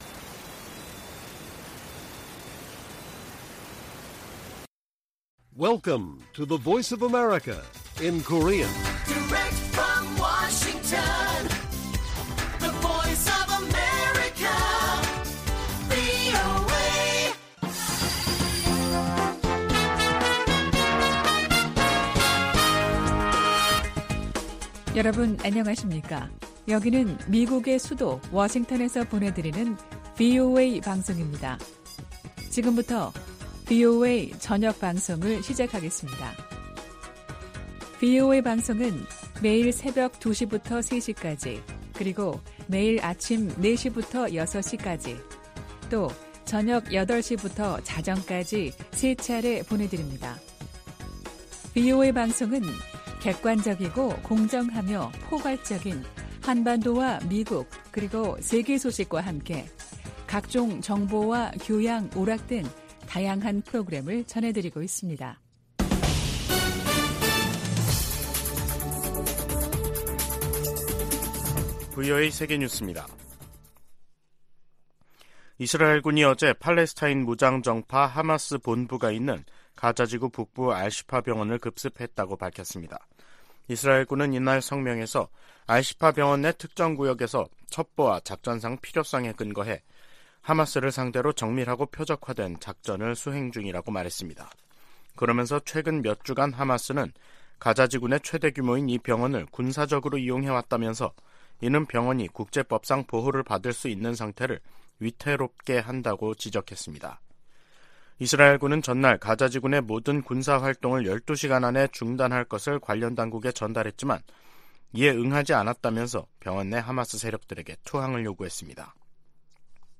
VOA 한국어 간판 뉴스 프로그램 '뉴스 투데이', 2023년 11월 15일 1부 방송입니다. 미국과 한국, 일본의 외교수장들이 미국에서 만나 중동 정세, 북한의 러시아 지원, 경제 협력 확대 등을 논의했습니다. 백악관은 조 바이든 대통령이 미중 정상회담과 관련해 대결과 외교 모두 두려워하지 않을 것이라고 밝혔습니다. 북한은 신형 중거리 탄도미사일, IRBM에 사용할 고체연료 엔진 시험을 성공적으로 진행했다고 밝혔습니다.